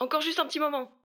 VO_ALL_Interjection_04.ogg